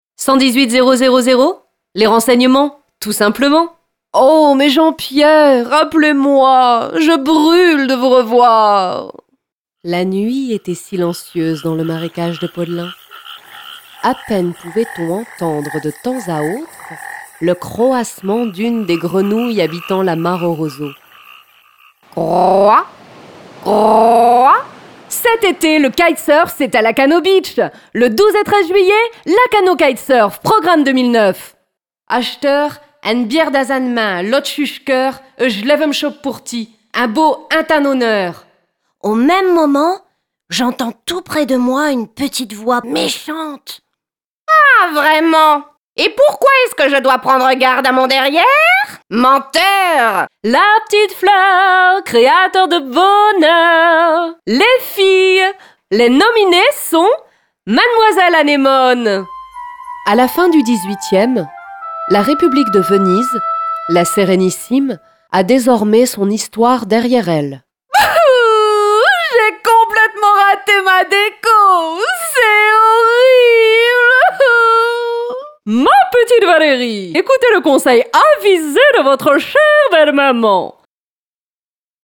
Bandes-son
Voix off
26 - 41 ans - Mezzo-soprano